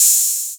OH808D10.wav